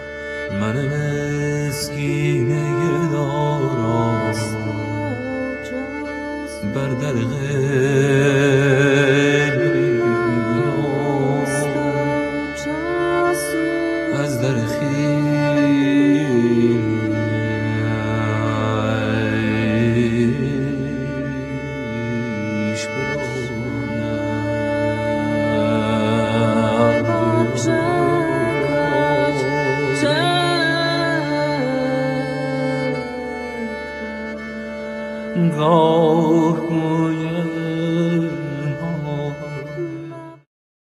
śpiew, padudla (fidel płocka), suka biłgorajska
skrzypce żłobione
cymbały
śpiew alikwotowy, bas
bębny
instrumenty klawiszowe